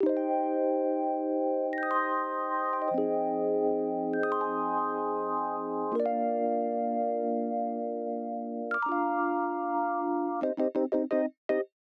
06 rhodes intro.wav